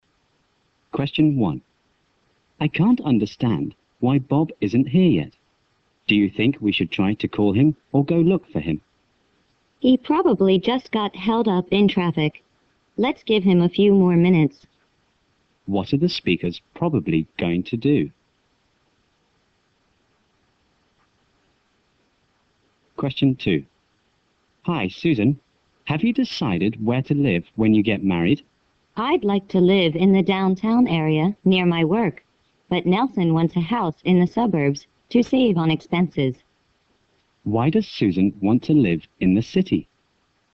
在线英语听力室094的听力文件下载,英语四级听力-短对话-在线英语听力室